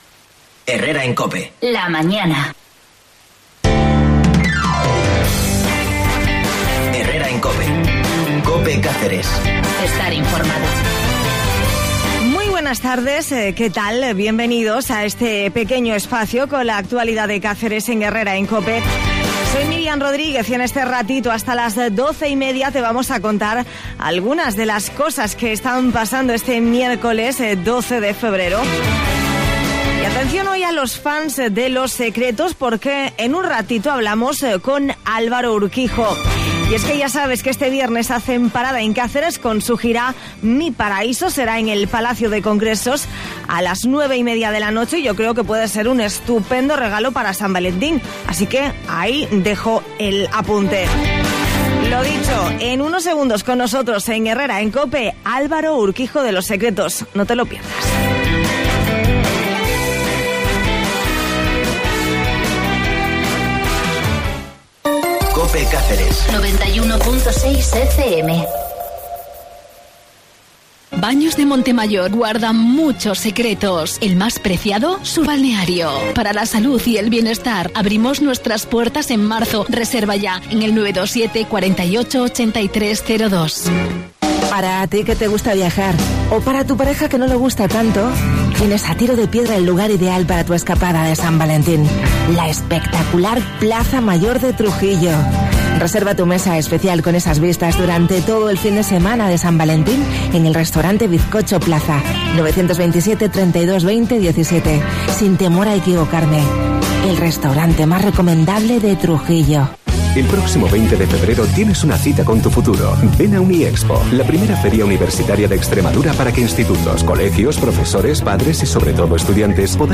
En Herrera en Cope hablamos con Los Secretos que este viernes presentan su nuevo disco en Cáceres